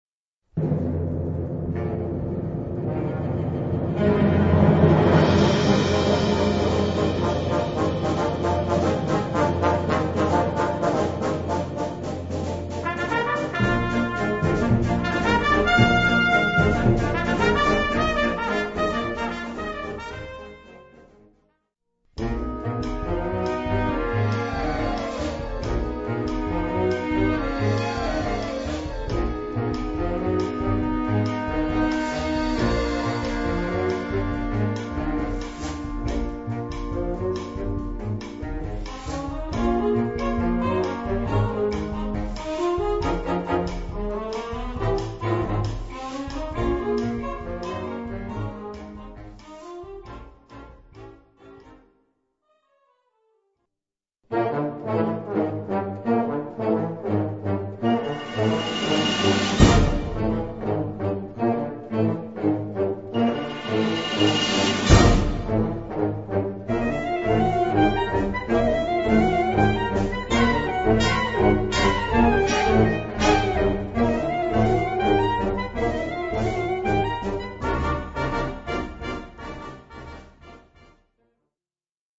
Categorie Harmonie/Fanfare/Brass-orkest
Subcategorie Originele hedendaagse muziek (20ste/21ste eeuw)
Bezetting Ha (harmonieorkest)